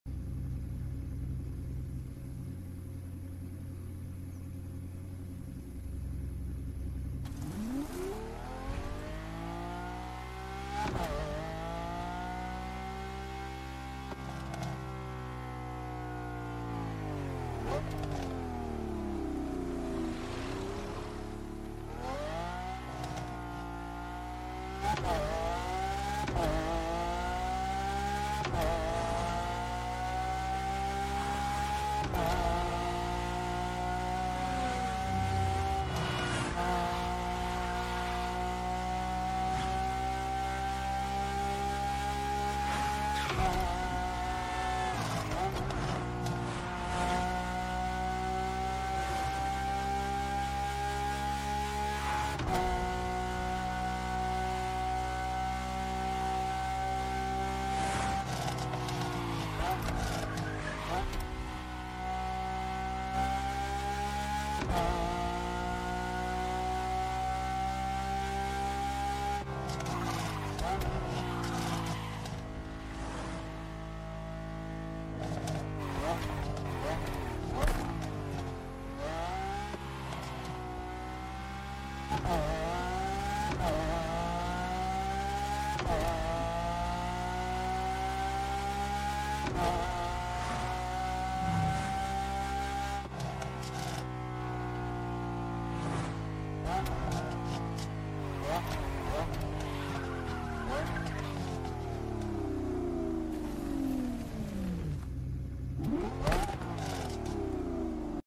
The V12 Sounds So GOOD!